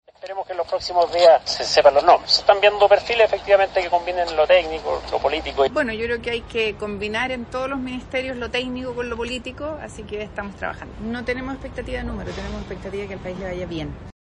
En ese contexto, los futuros ministros de Obras Públicas, Martín Arrau, y de Energía, Ximena Rincón, señalaron que actualmente se están revisando perfiles que combinen experiencia técnica con trayectoria política.